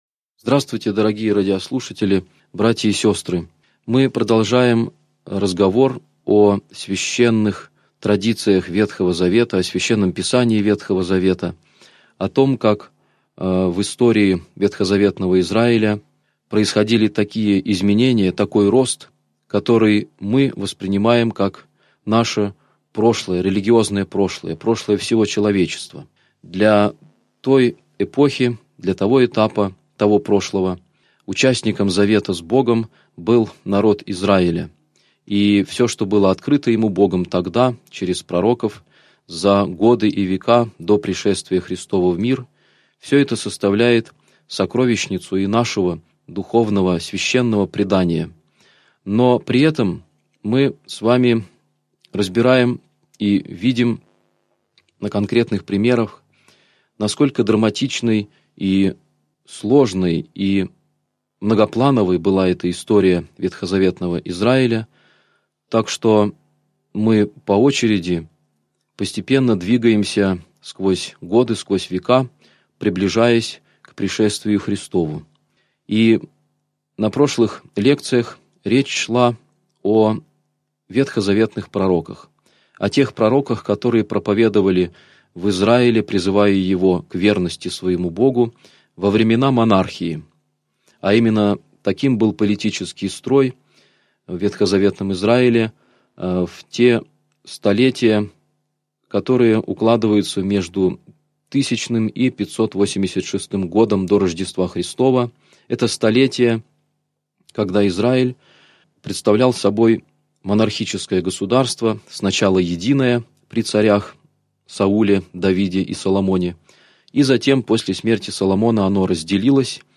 Аудиокнига Лекция 11. Об Имени Божием | Библиотека аудиокниг